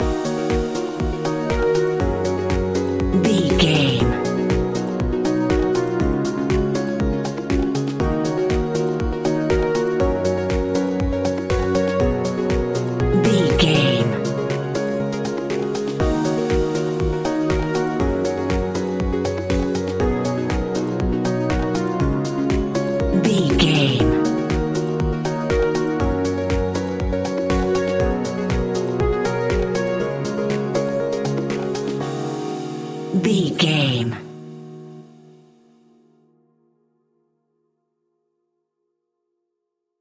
Aeolian/Minor
E♭
groovy
uplifting
hypnotic
dreamy
smooth
piano
drum machine
synthesiser
electro house
synth leads
synth bass